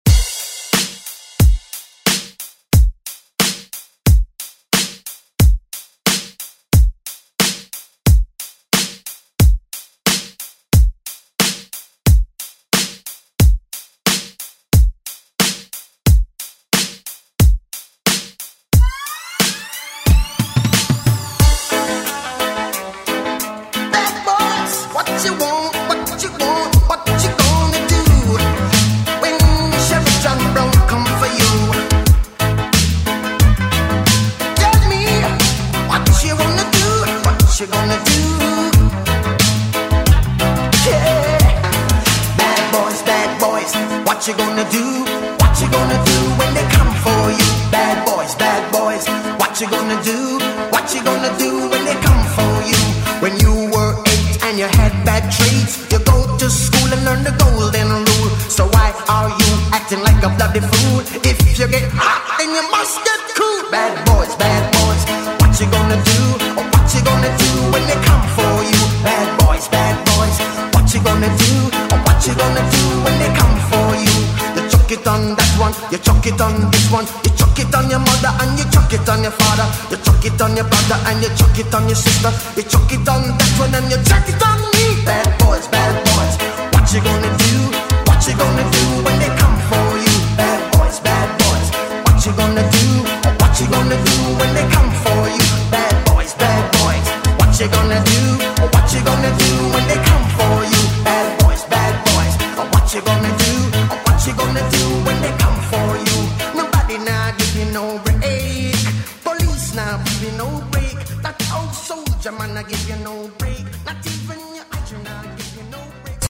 Throwback RnB Funk Soul Music
Extended Intro Outro
91 bpm
Genres: 2000's , R & B